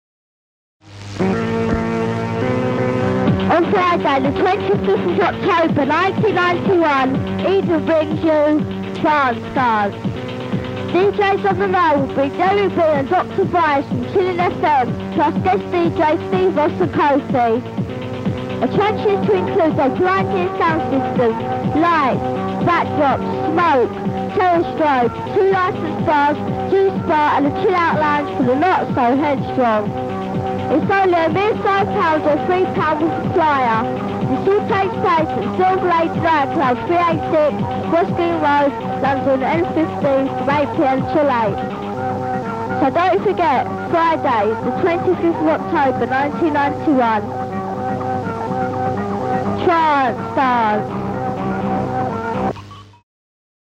More tapes recorded in London, a few months after the first batch.